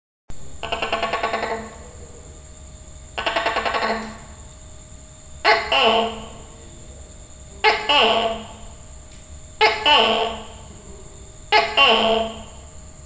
Звук токи поющего геккона